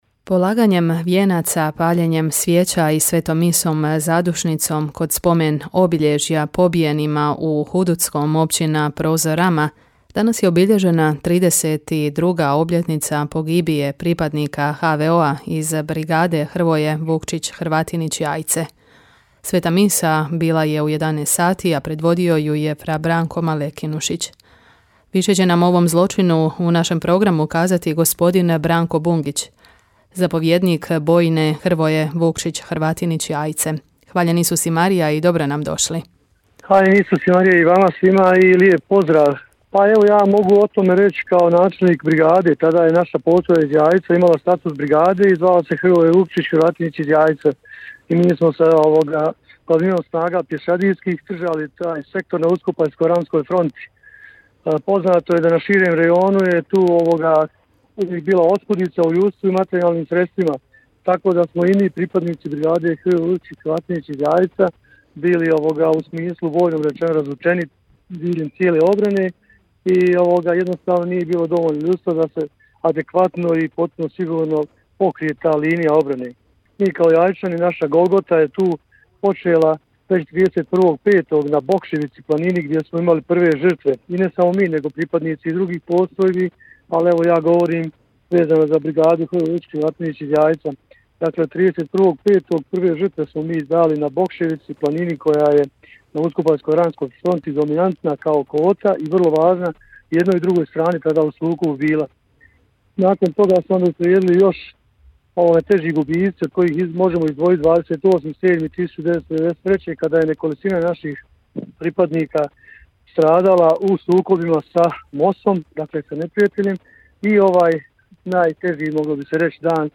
U Popodnevnom mozaiku danas nam je gost bio